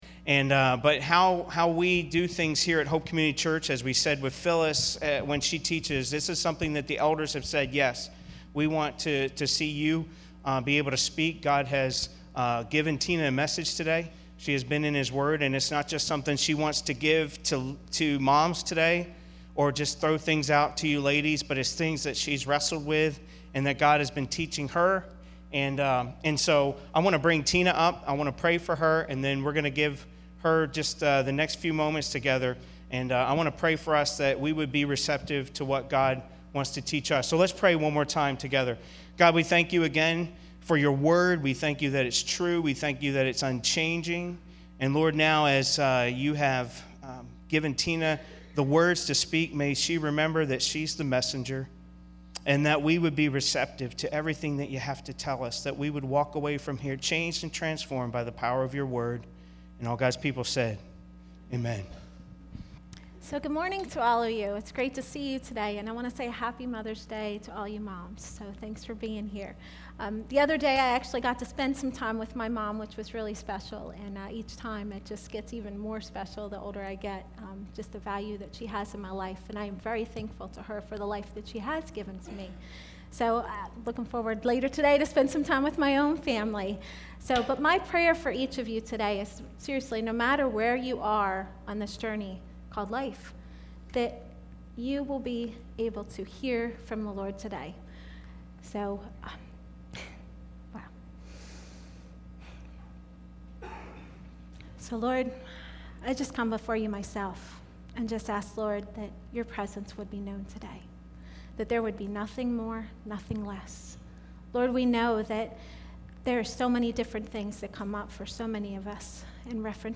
Mother's Day message 2014